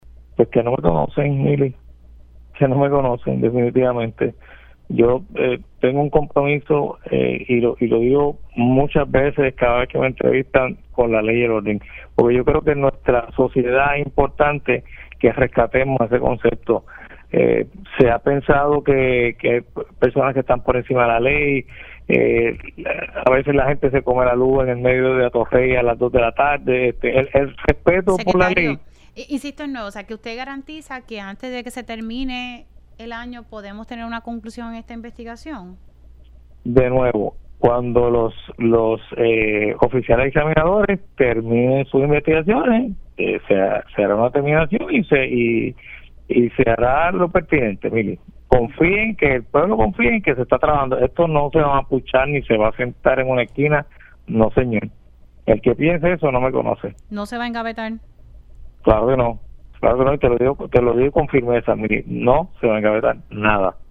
El secretario del Departamento de Recursos Naturales y Ambientales (DRNA), Waldemar Quiles, reaccionó en RADIO ISLA 1320 a la investigación que se llevó a cabo en su contra por una orden ejecutiva que archivó casos relacionados con construcciones ilegales en La Parguera, Lajas.